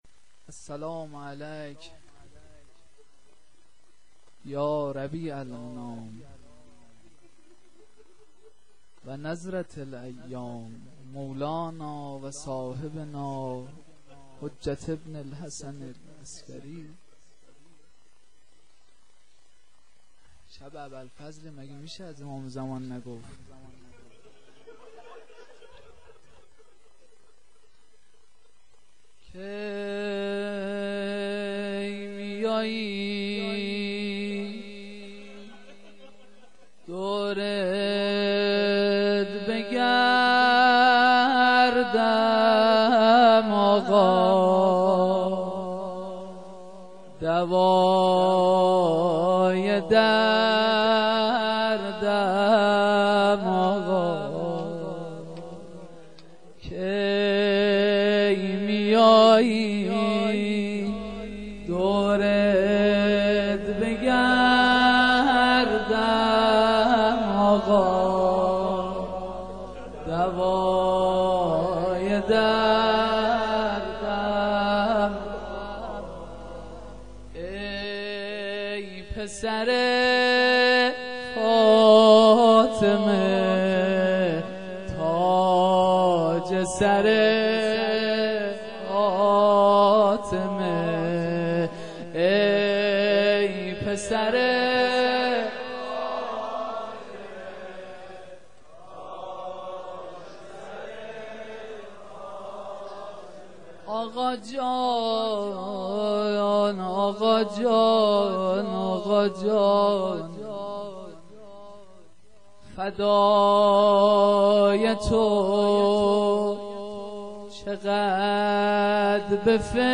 جلسه مذهبی زیارت آل یاسین باغشهر اسلامیه
مناجات - شب تاسوعا محرم 95-01